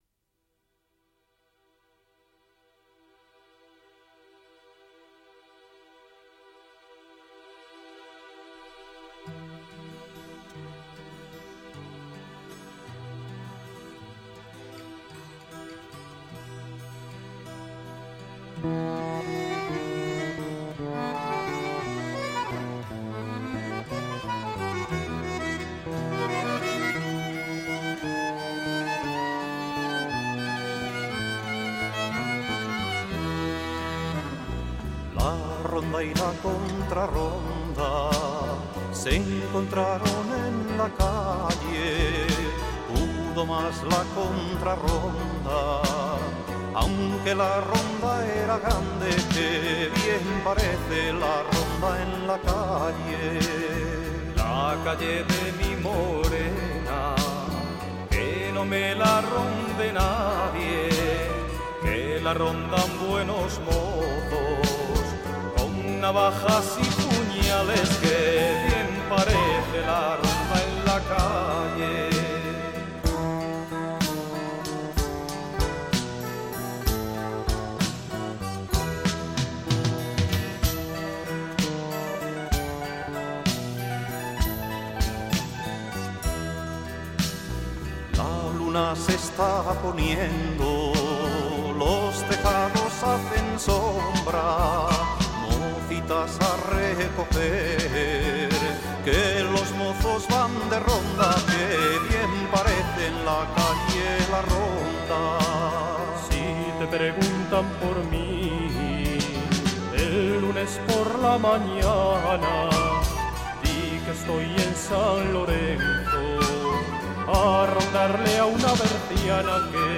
1987-Tahona-Cancion-de-ronda.mp3